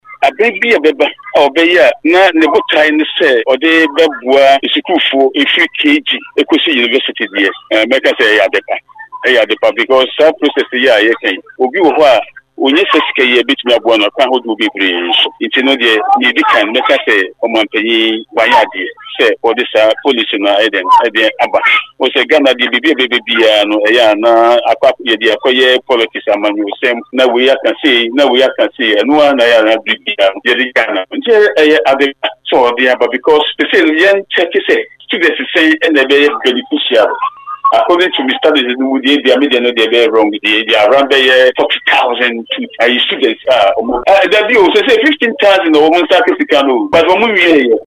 Speaking on Radio1’s morning show